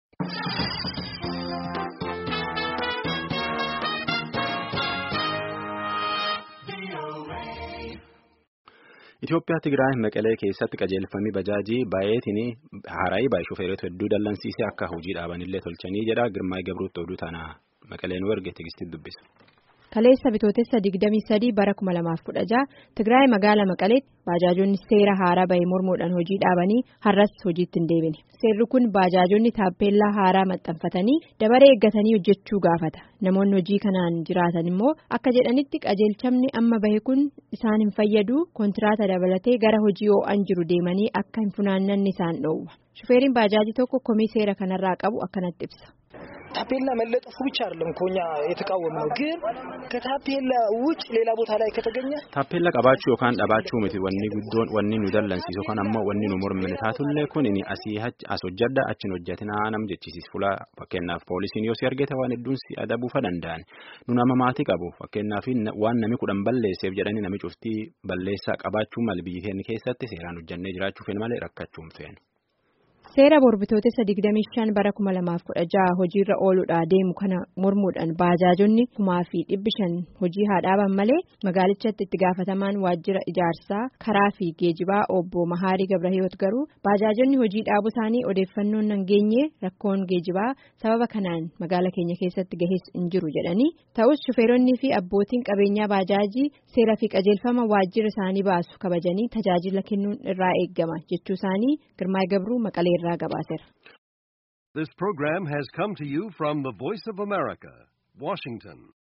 Gabaasa sagalee.